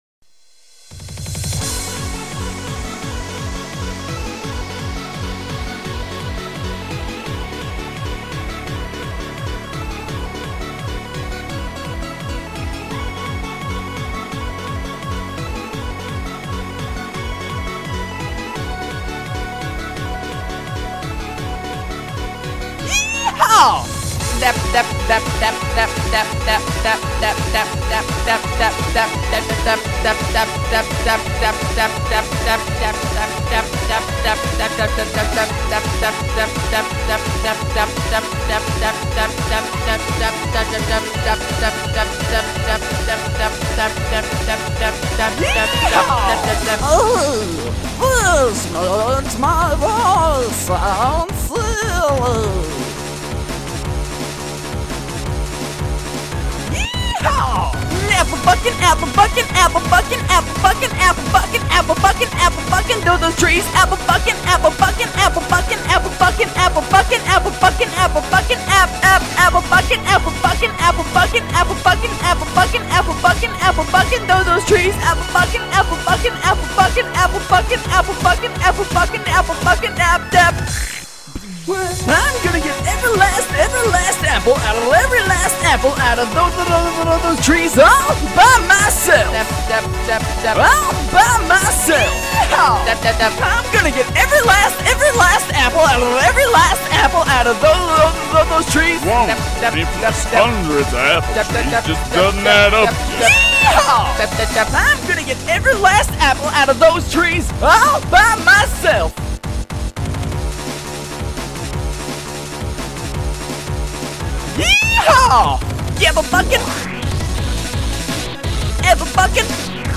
My timing in this song is horrible.